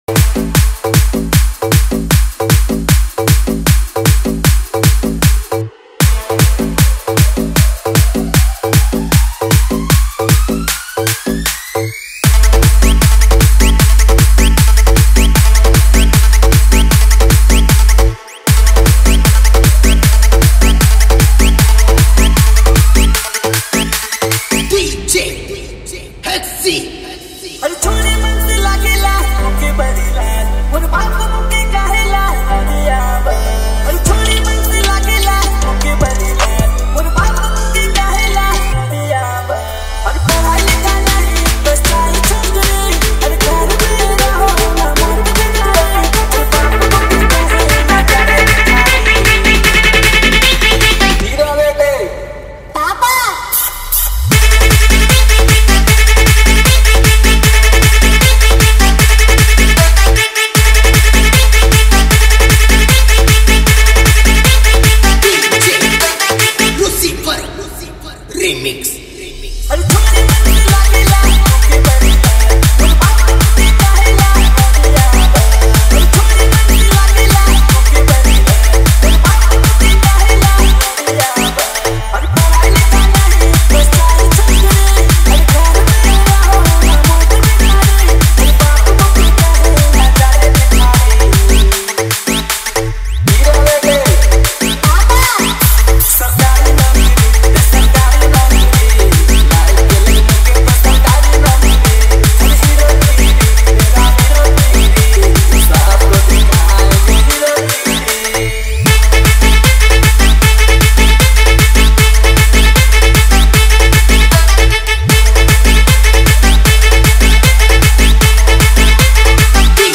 • Category: ODIA SINGLE REMIX